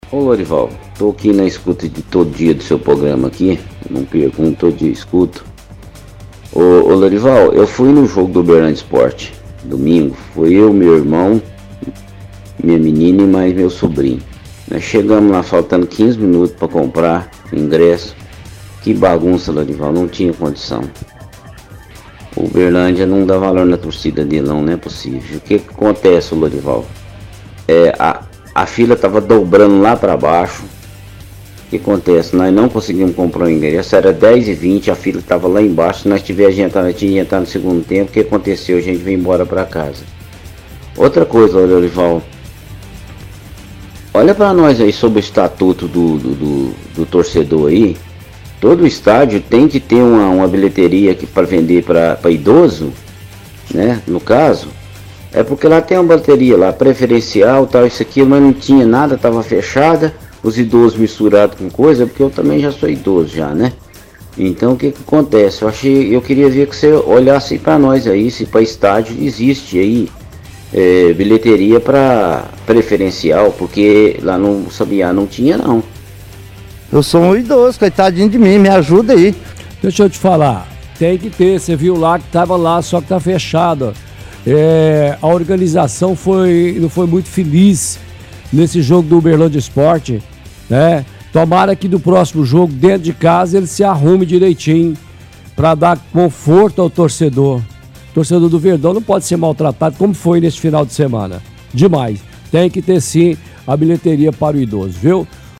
– Ouvinte reclama de demora em venda de ingressos no jogo do UEC.